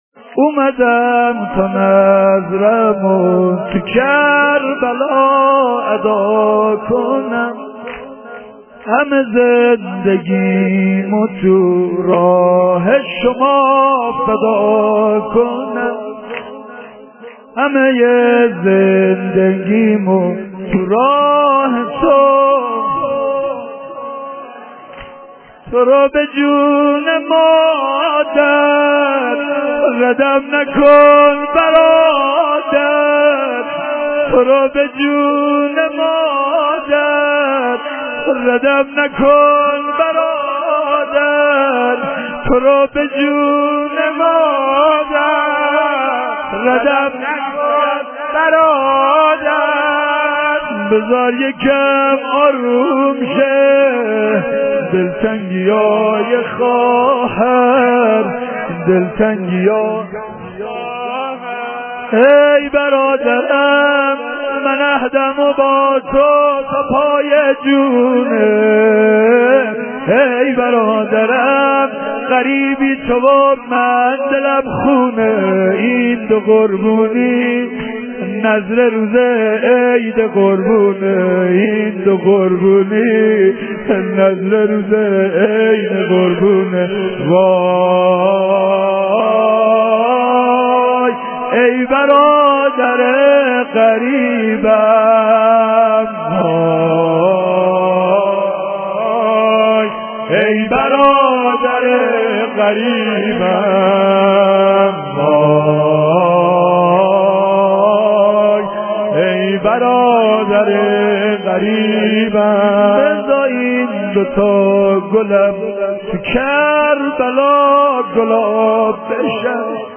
SINE ZANI SHAB 4.lite.mp3